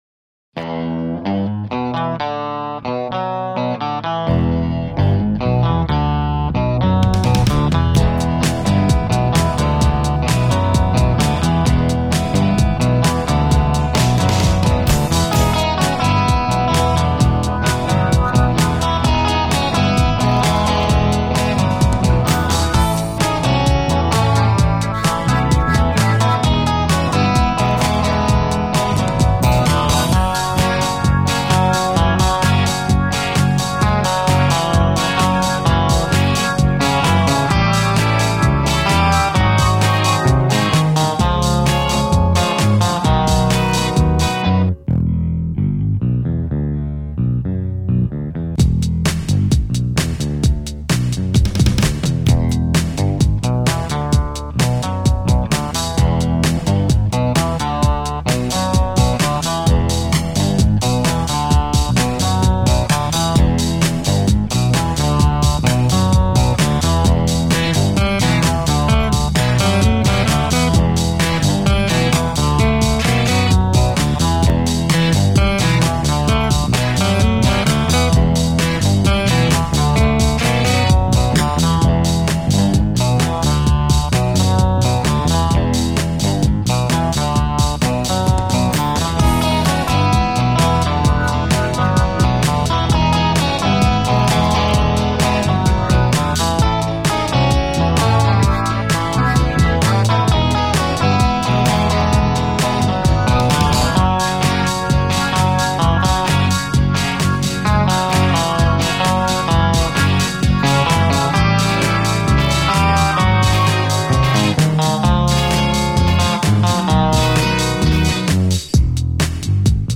Do basy je zabudován Behringer Overdrive BOD 400 s ovládacími prvky: Gain, Bal, High, Low, Level, napájený baterií 9V.
Basa má velmi dlouhý sustain a mnoho možností volby zvukových barev.